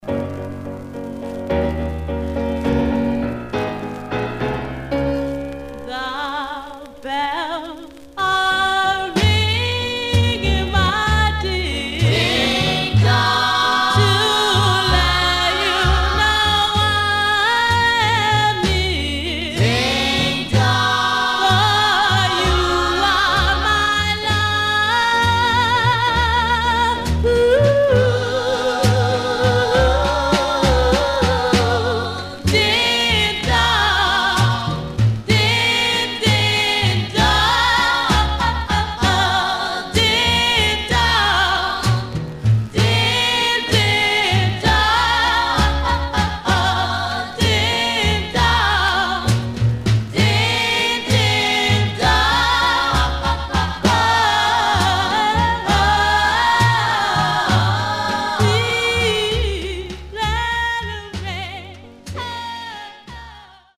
Some surface noise/wear Stereo/mono Mono
Black Female Group Condition